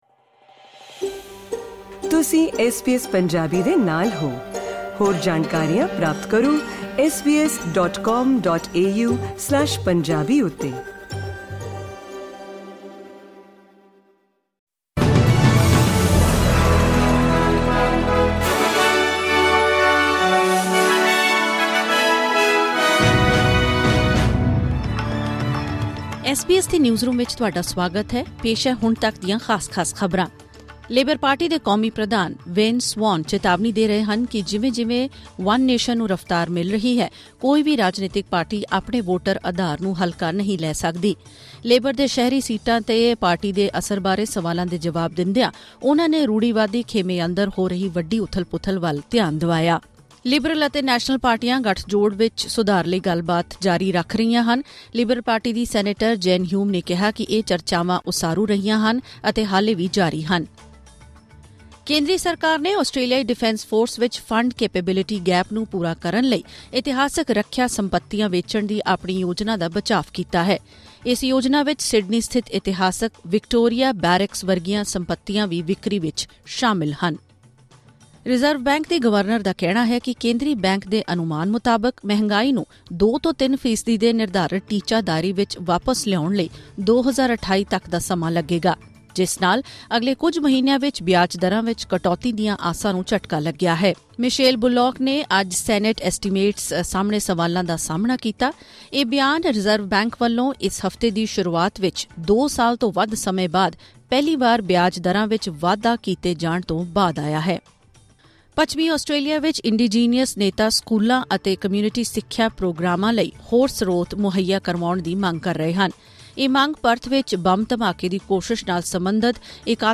ਖ਼ਬਰਨਾਮਾ: ਰਿਜ਼ਰਵ ਬੈਂਕ ਦੇ ਅਨੁਮਾਨਾਂ ਅਨੁਸਾਰ ਮਹਿੰਗਾਈ ਨੂੰ ਕਾਬੂ ਕਰਨ ਵਿੱਚ ਸਮਾਂ ਲੱਗੇਗਾ